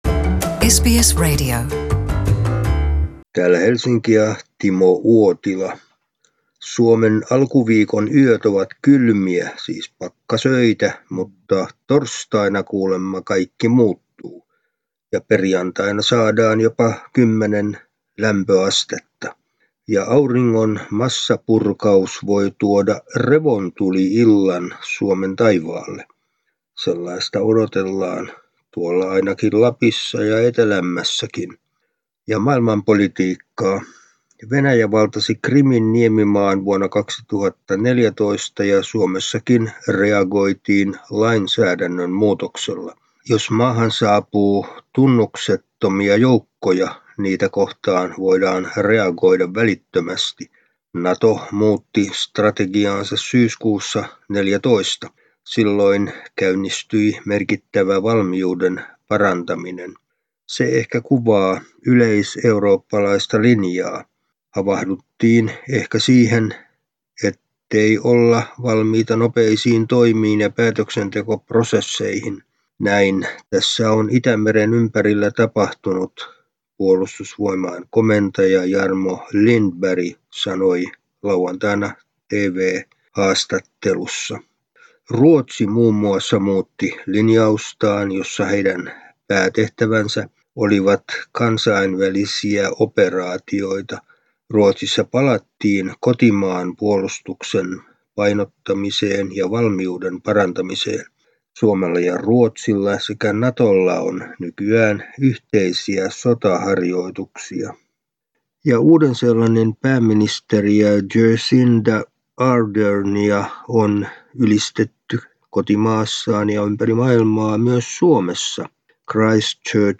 ajankohtaisraportti Suomesta